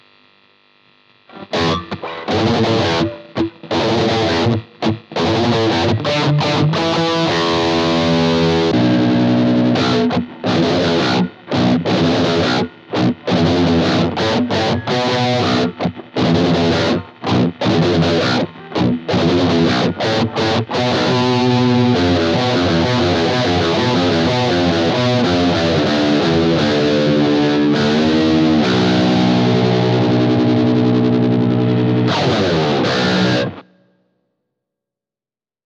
TUNE DOWN
レイテンシー（音の遅延）はというと、残念ながら感じます。
また、トレモロのように周期的に音量が変化するように聞こえます。
エフェクトOFFから弾き始め途中でONにしています。
擬似７弦ギター（設定　−５